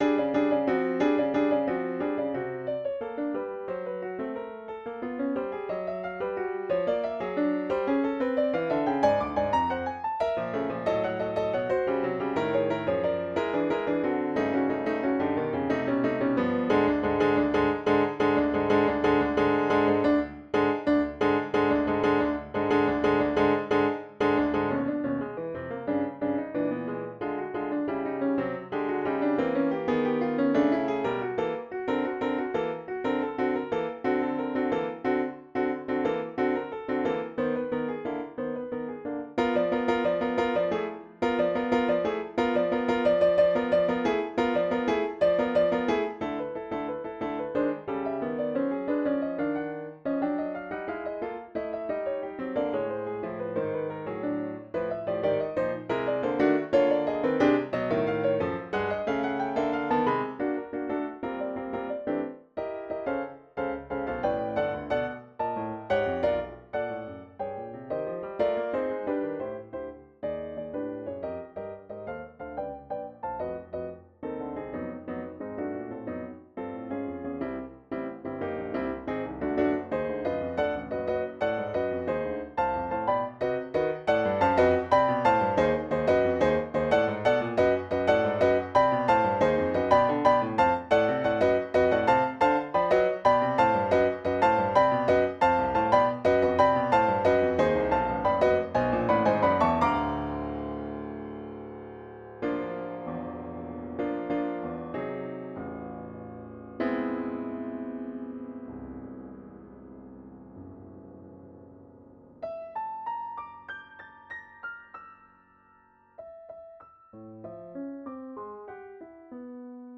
Piano Sonata No2 Finale Sequence - Sonata in one movement.